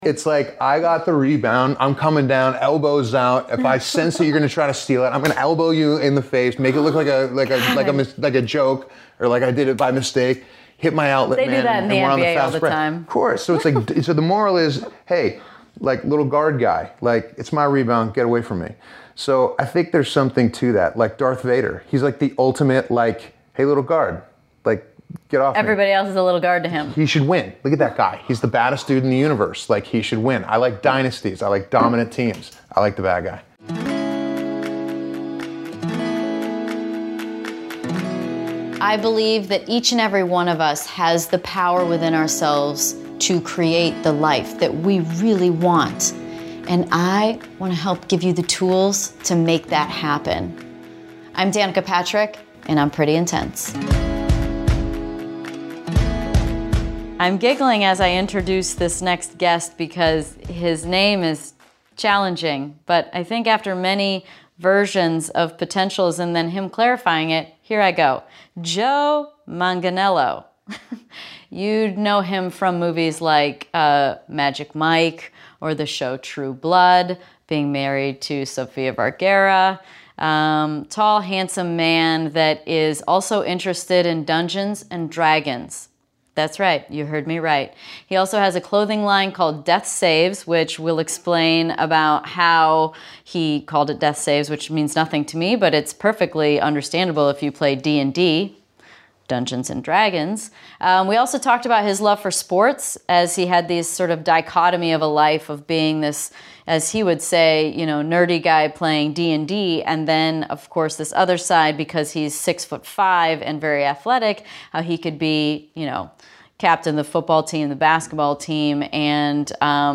It was a really, really fun conversation.